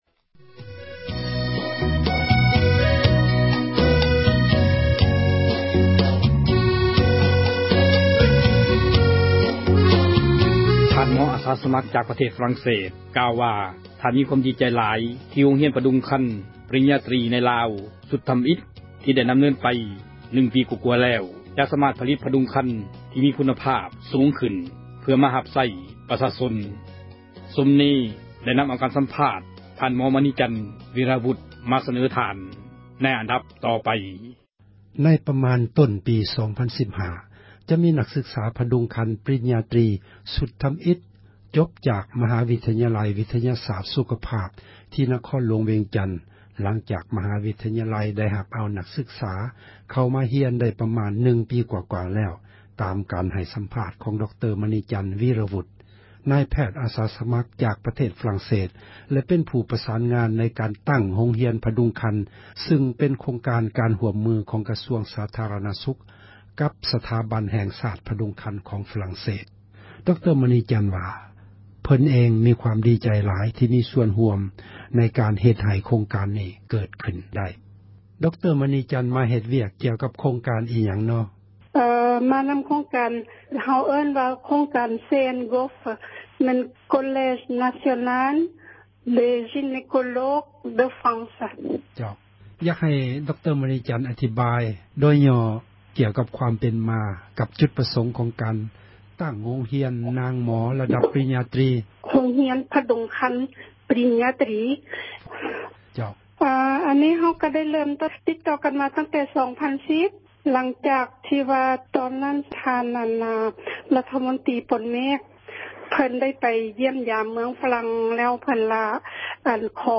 ມີສັມພາດ